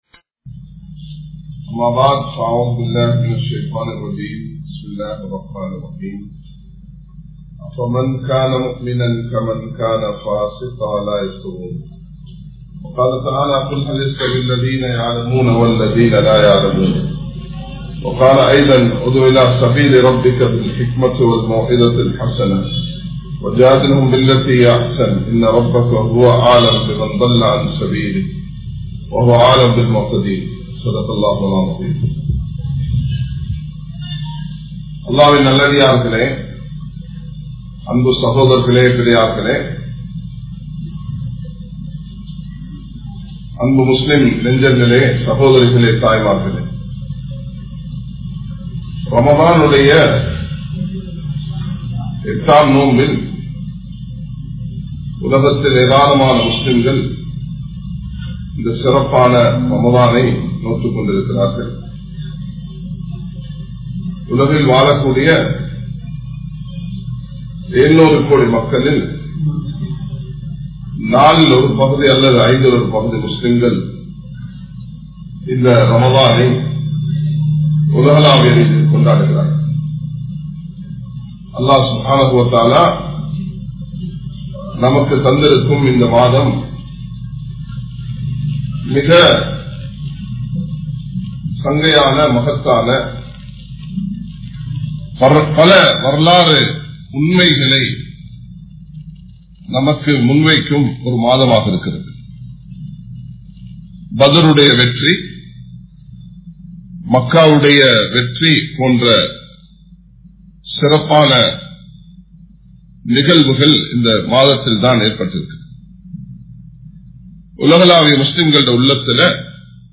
Islaamiya Sattangal Theeviravaatham Illai(இஸ்லாமிய சட்டங்கள் தீவிரவாதம் இல்லை) | Audio Bayans | All Ceylon Muslim Youth Community | Addalaichenai
Colombo 10, Maligawaththe, Mathrasathul Thullab Arabic College